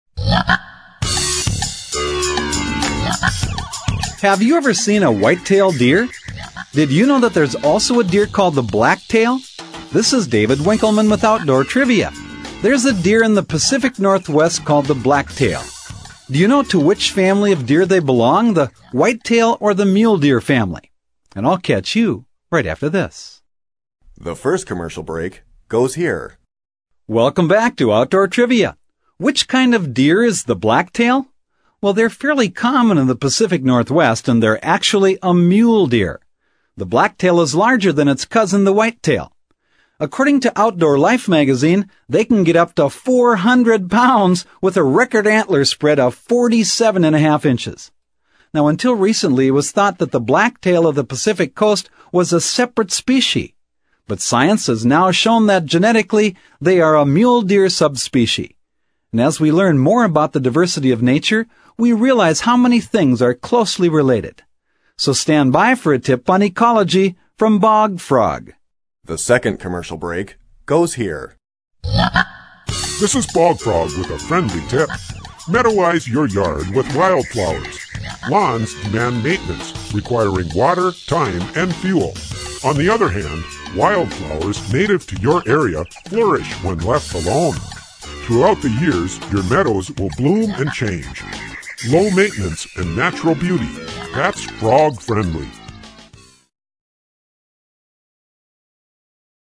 In fact, the question and answer trivia format of this program remains for many people, a most enjoyable, yet practical method of learning.
Programs are 2 1/2 minutes long, including commercial time. Bog Frog’s Tips conclude each program.
Bog Frog's voice is distinctive and memorable, while his messages remain positive and practical, giving consumers a meaningful symbol to remember.